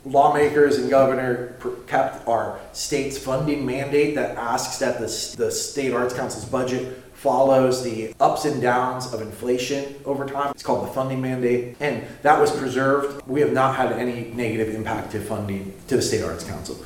The meeting took place at the Gallery Stage on North Centre Street with 14 in attendance.